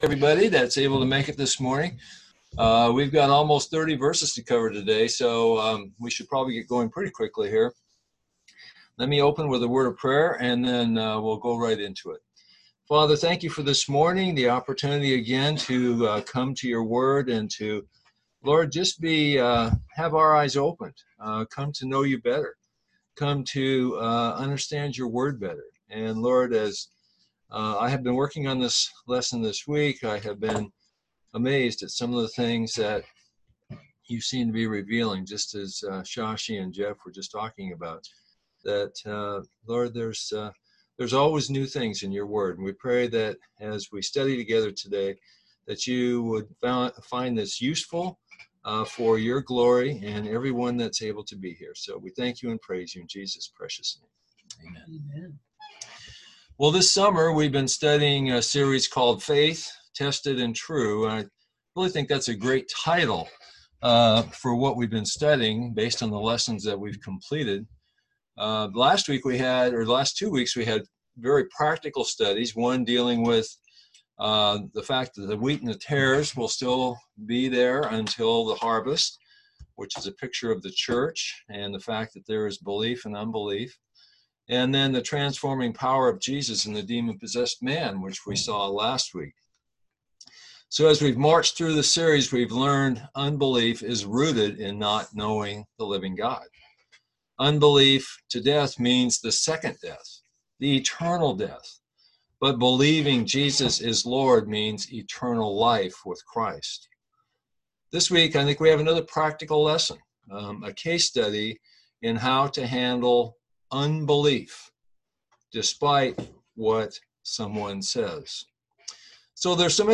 John 8:31-59 Service Type: Sunday School « Lesson 8